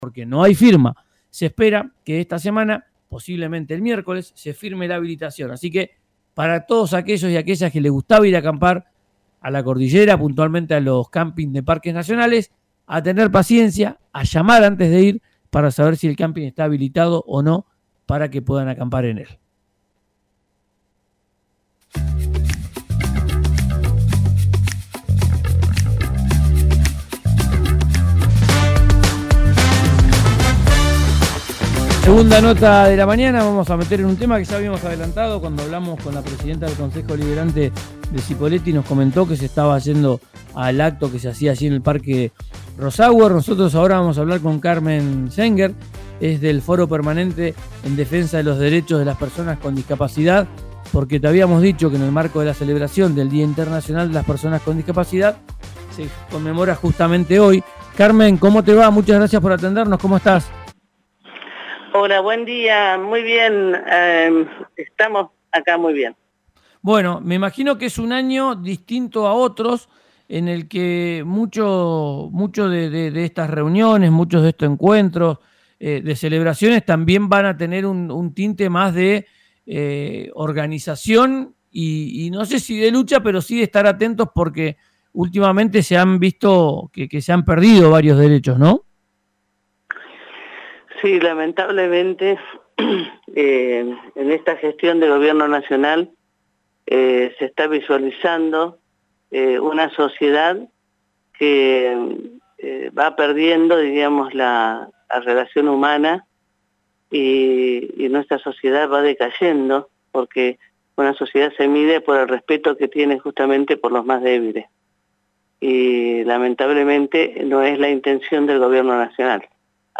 habló en » Ya es Tiempo» por RÍO NEGRO RADIO